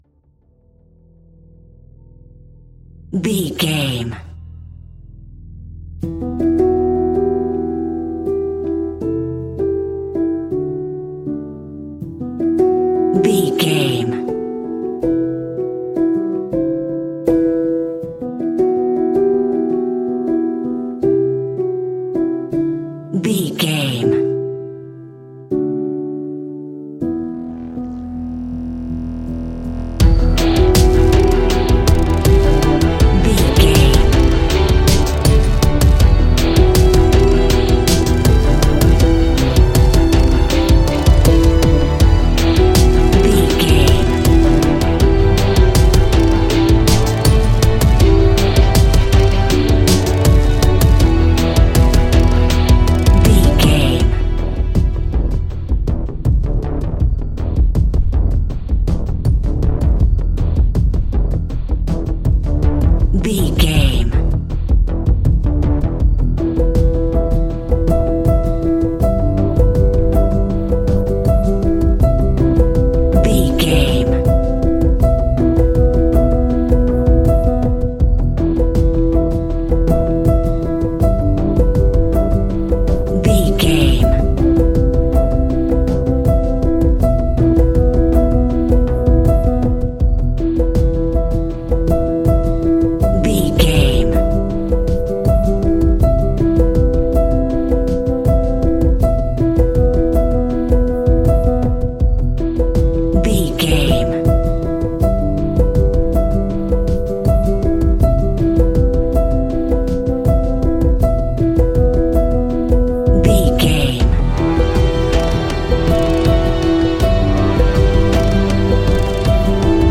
Aeolian/Minor
dramatic
epic
strings
percussion
synthesiser
brass
violin
cello
double bass